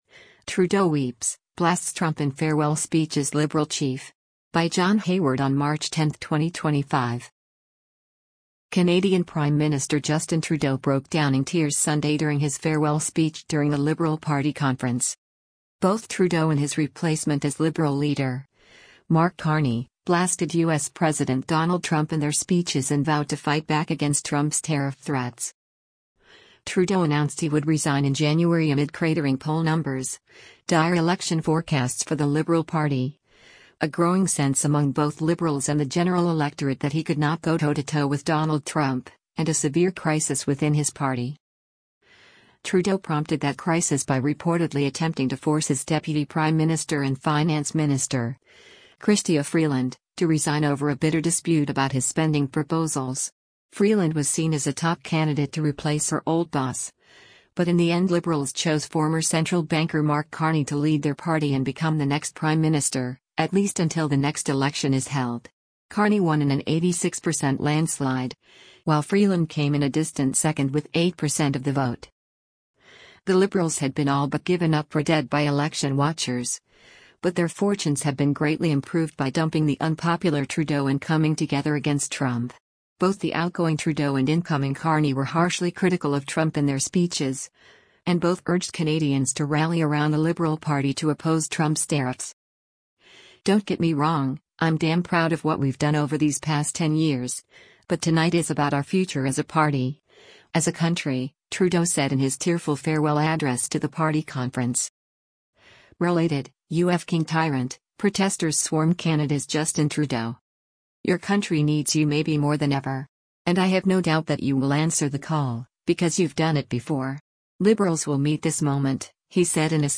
Canadian Prime Minister Justin Trudeau broke down in tears Sunday during his farewell speech during a Liberal Party conference.